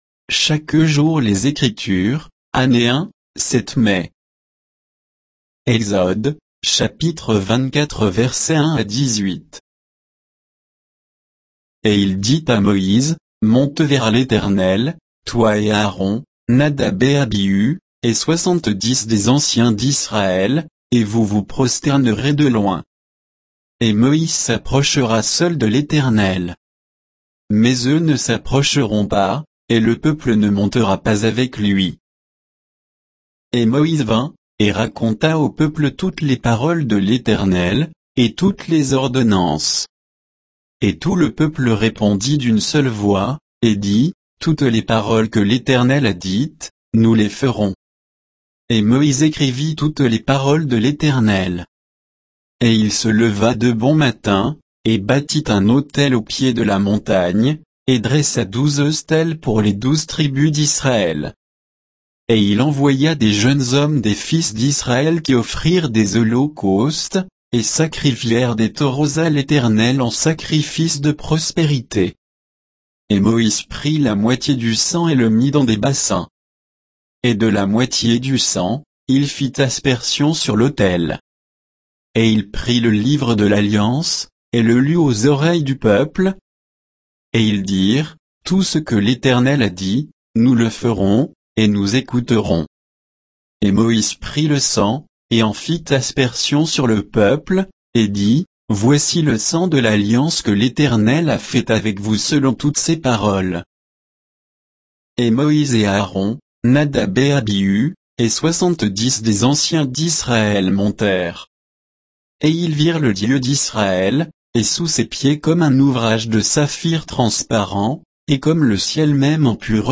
Méditation quoditienne de Chaque jour les Écritures sur Exode 24, 1 à 18